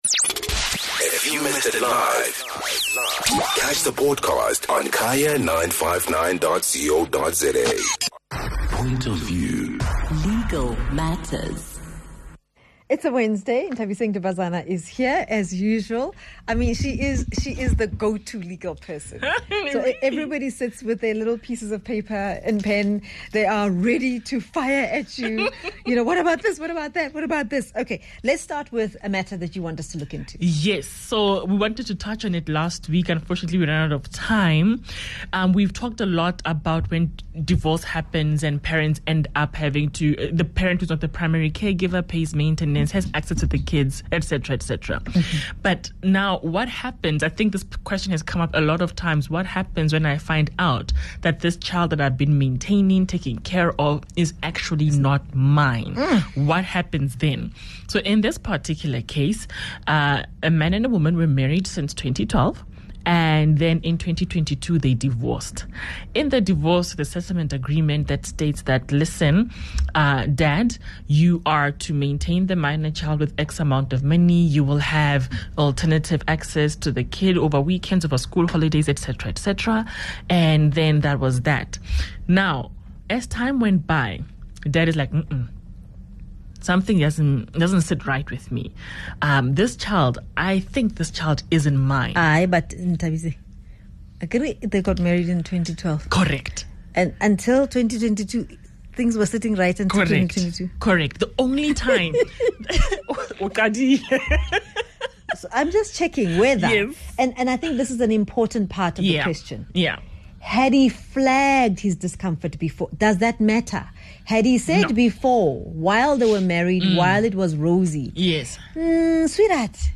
in studio, this week she discusses Parental Rights termination.